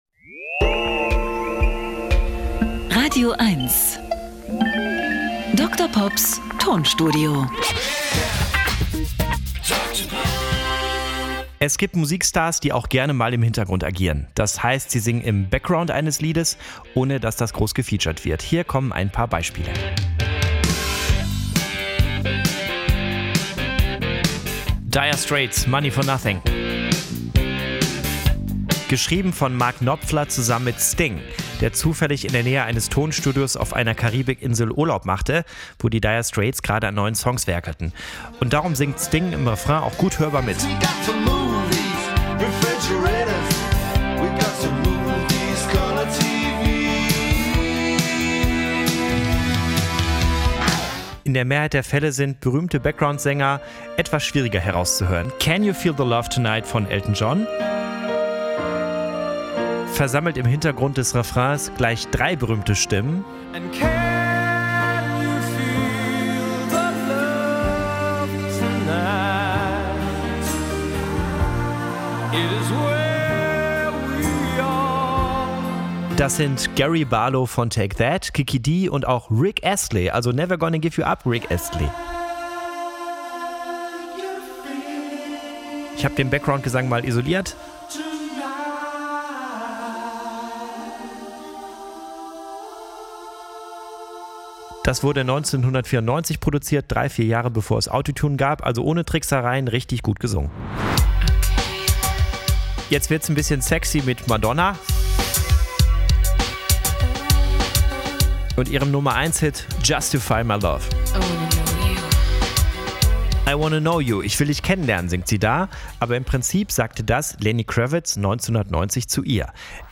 Comedy und Kabarett.
Er therapiert mit Musiksamples und kuriosen, aber völlig wahren Musikfakten. Er entschlüsselt, welche Bedeutung der Buchstabe B in der Erfolgsgeschichte großer Künstler von Beethoven über die Beatles bis hin zu Justin Bieber hat.